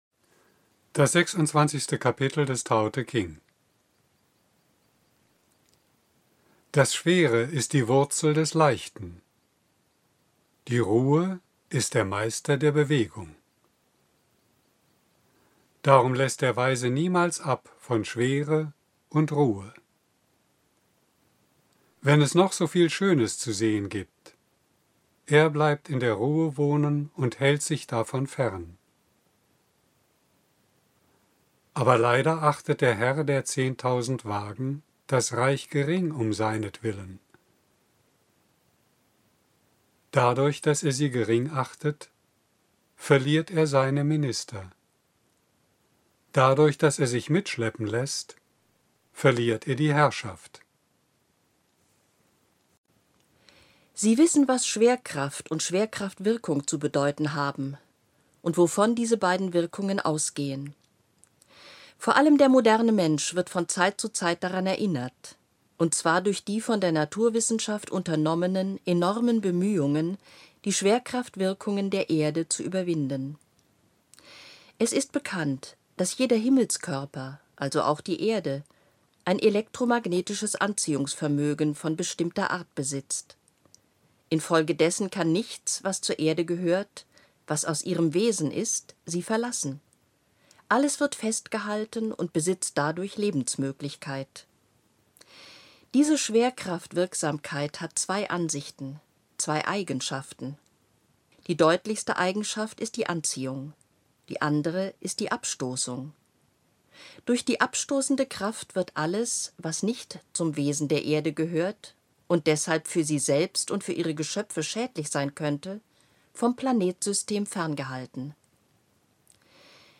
Die CD "Die Chinesische Gnosis" ist ein Hörbuch, das dem Text des Buches eine zusätzliche Lebendigkeit verleiht und dem Zuhörer ein direktes Erlebnis wie in einem vertrauten persönlichen Gespräch vermittelt.